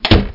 Doorclose Sound Effect
Download a high-quality doorclose sound effect.
doorclose.mp3